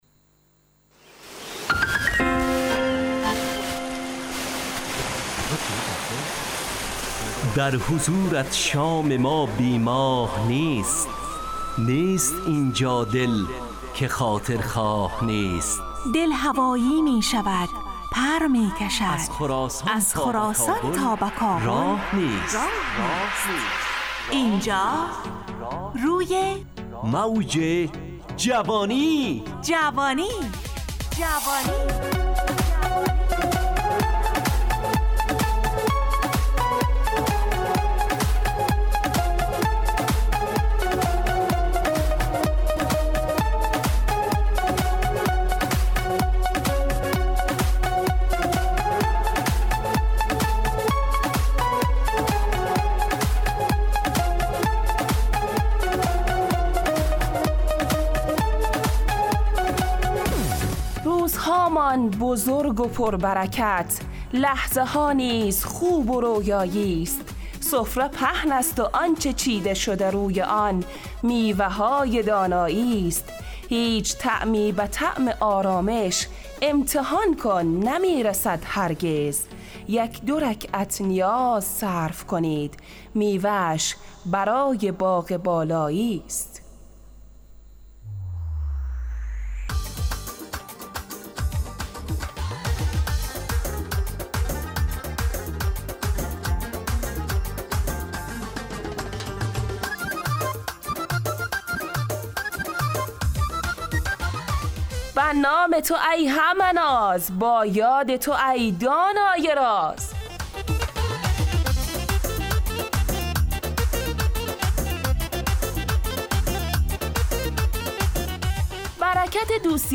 همراه با ترانه و موسیقی مدت برنامه 70 دقیقه . بحث محوری این هفته (امانت)